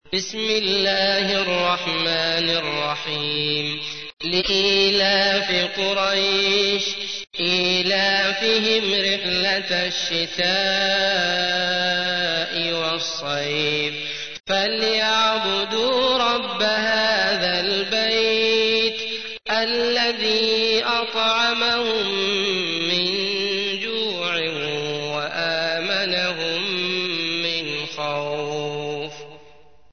تحميل : 106. سورة قريش / القارئ عبد الله المطرود / القرآن الكريم / موقع يا حسين